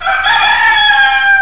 Silly Sound Files - Roosters
Rooster 9 - 11kb
rooster9.wav